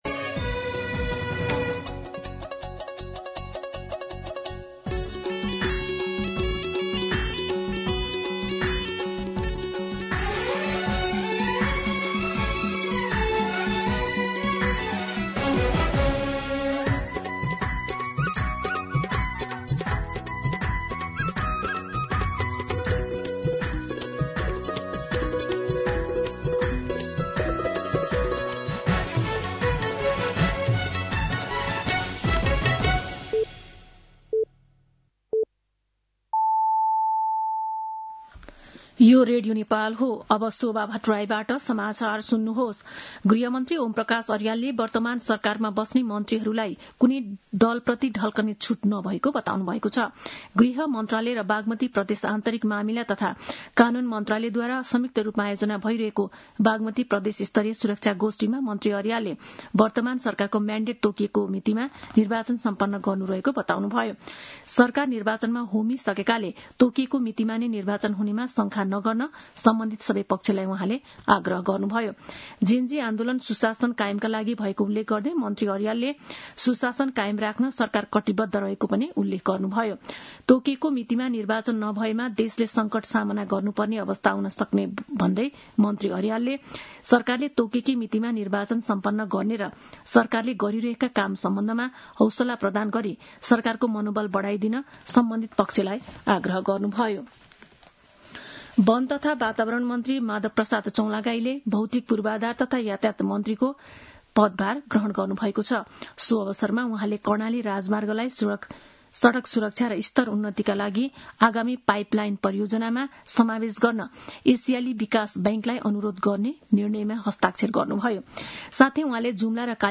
दिउँसो ४ बजेको नेपाली समाचार : २५ पुष , २०८२
4-pm-News-9-25.mp3